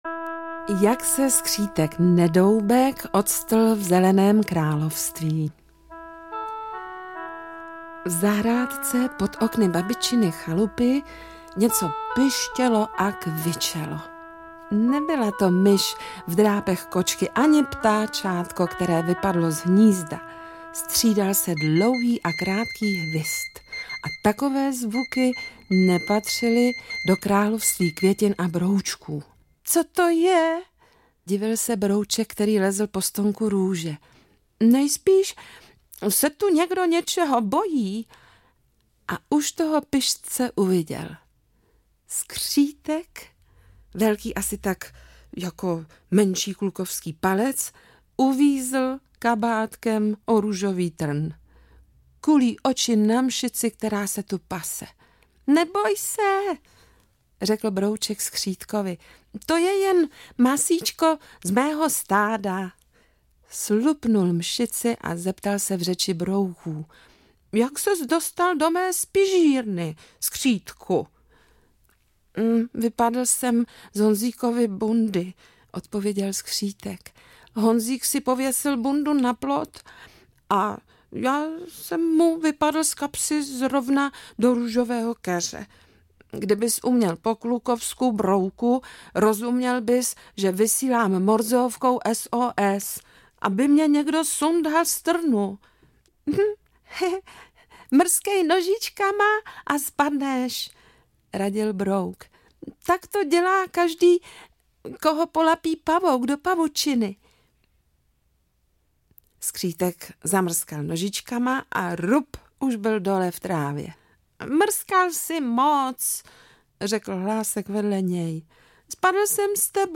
Délka: 34 min Interpret: Aňa Geislerová Vydavatel: SKIBI Vydáno: 2019 Série: Pohádky pro děti Skibi Kids Jazyk: český Typ souboru: MP3 Velikost: 32 MB
Jak napadal sníh – milá vánoční audiopohádka se zvířátky, kterou načetla Aňa Geislerová.